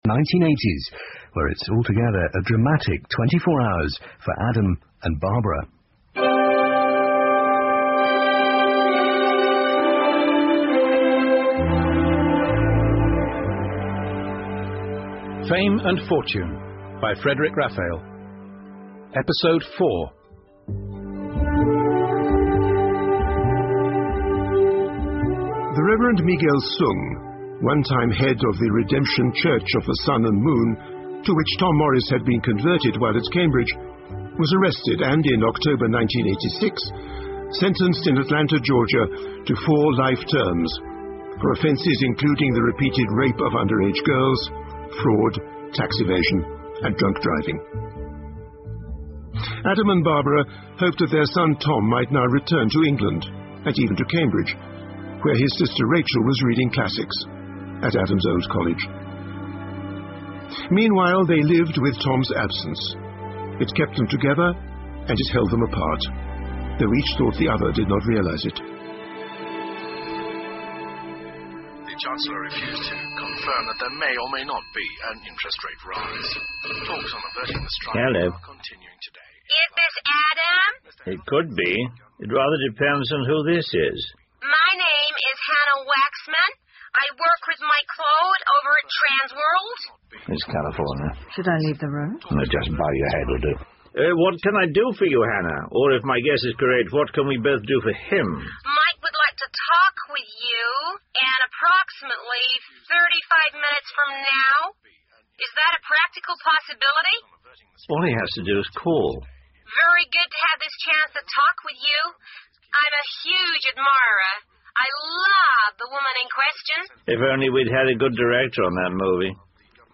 英文广播剧在线听 Fame and Fortune - 34 听力文件下载—在线英语听力室